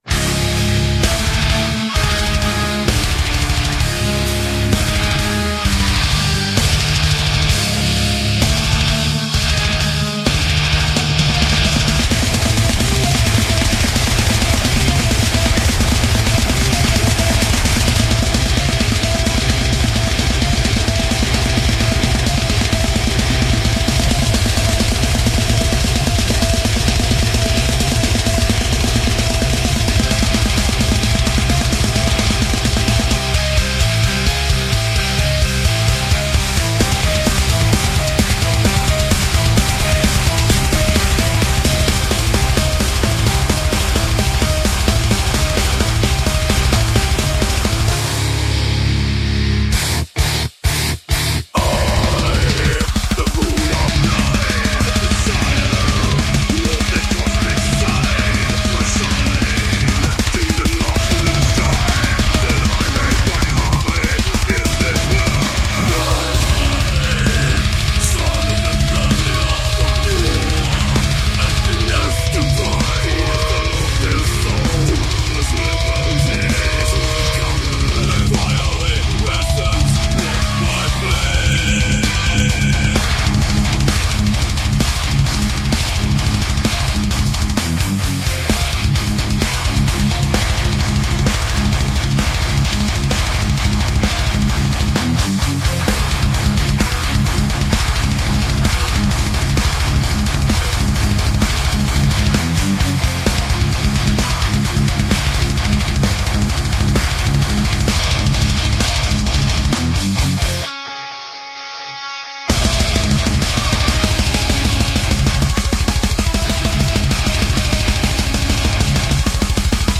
moon.death_metal.mp3